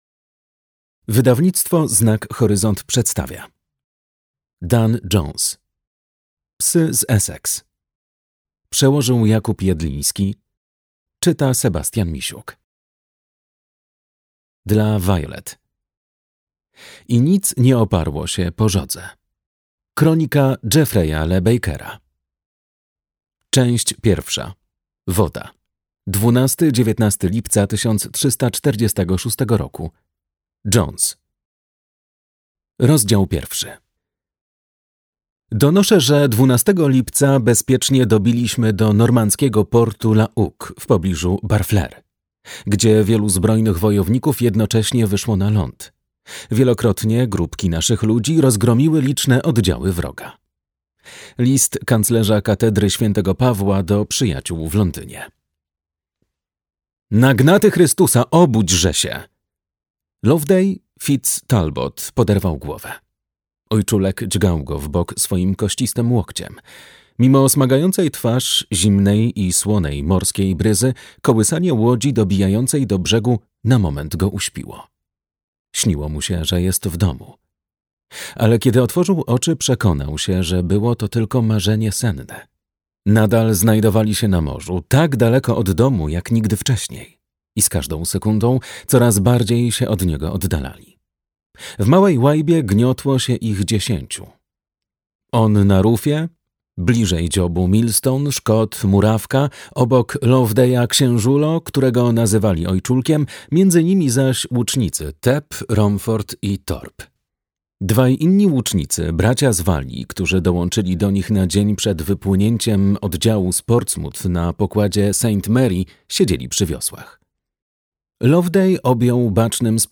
Psy z Essex - Dan Jones - audiobook